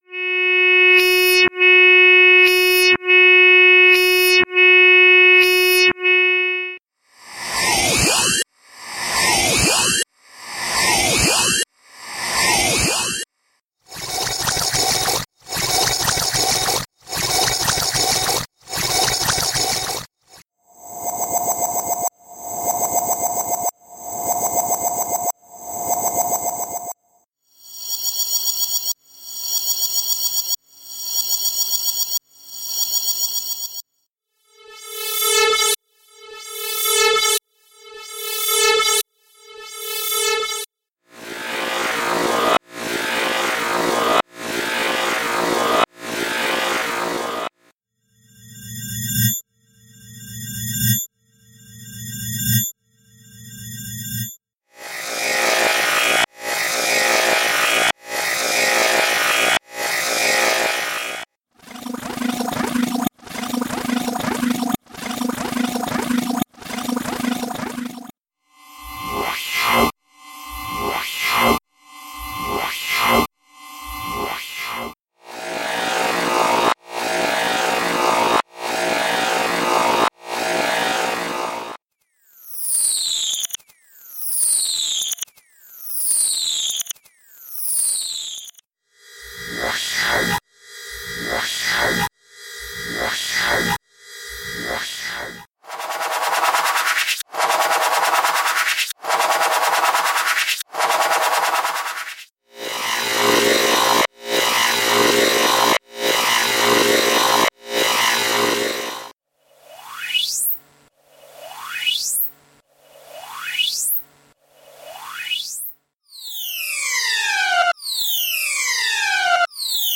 Sound Effects - SIGNALS - V7- p2
Signals Actual Length: 1 Minute (60 Sec) Each Sound.